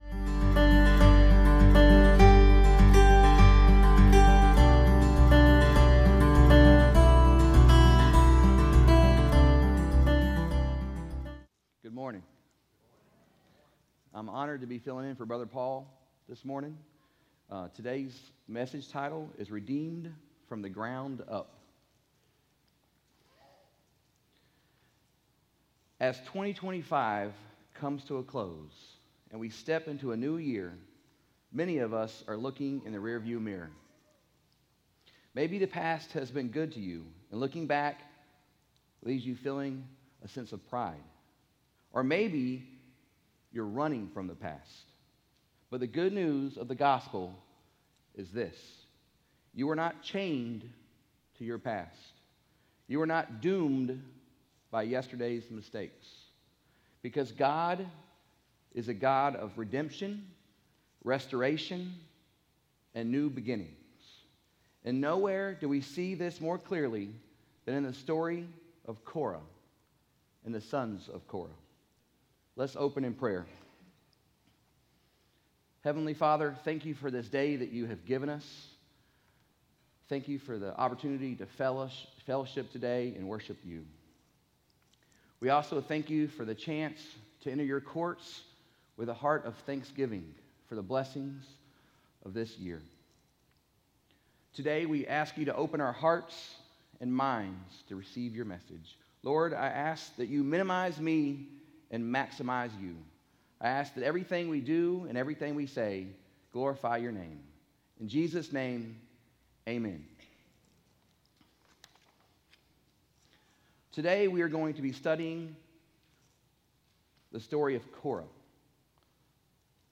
From Series: "Sunday AM"